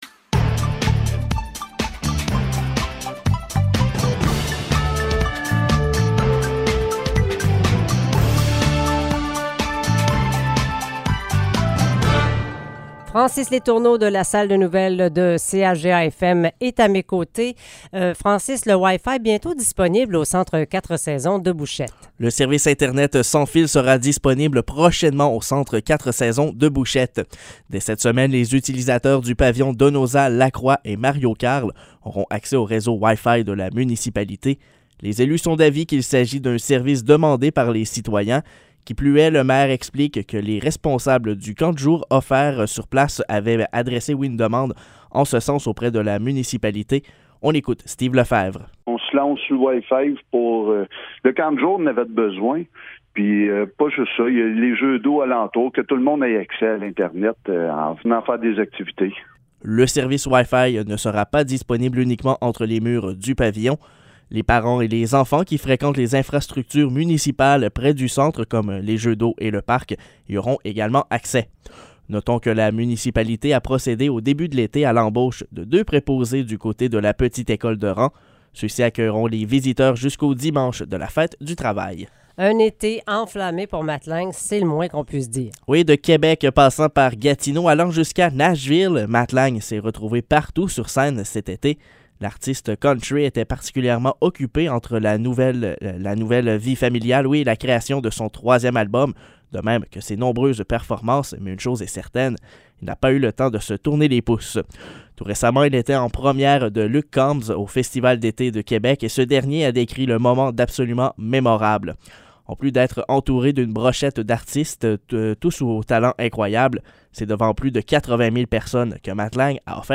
Nouvelles locales - 9 août 2022 - 7 h